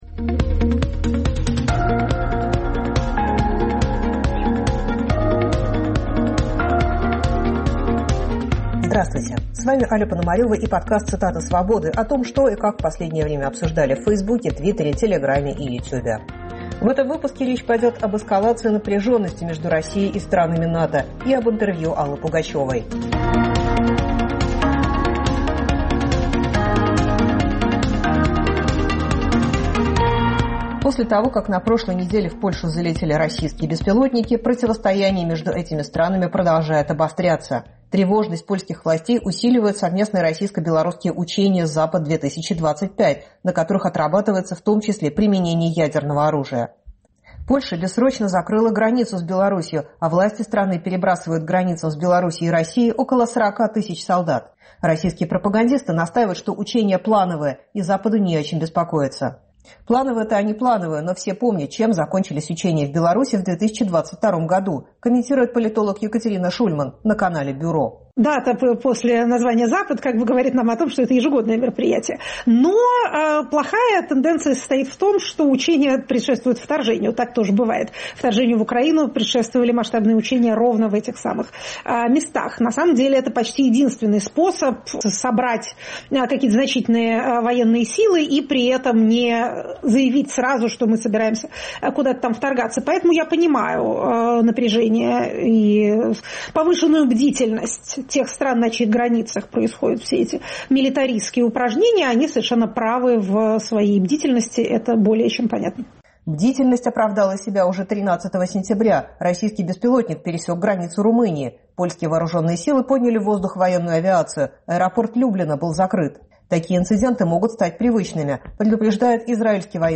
Россия провоцирует НАТО. Интервью Аллы Пугачевой